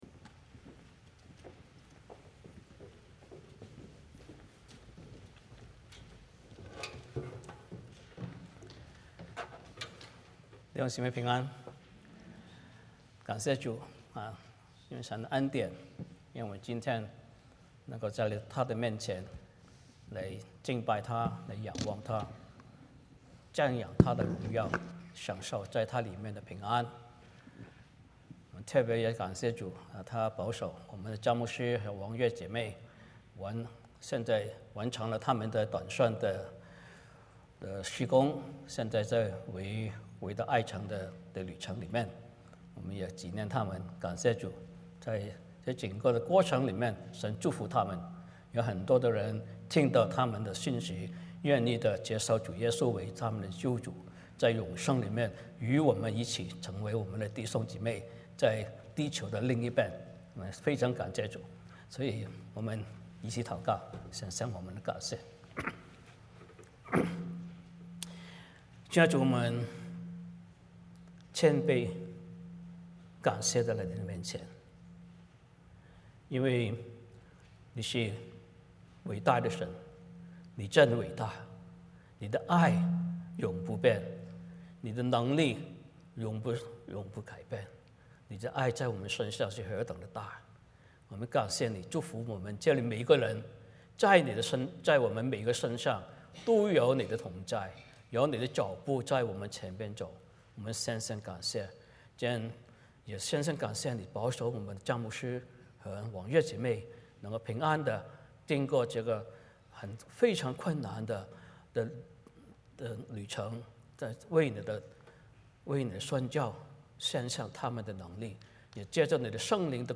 欢迎大家加入我们国语主日崇拜。
Passage: 彼得前书 2: 1-10 Service Type: 主日崇拜 欢迎大家加入我们国语主日崇拜。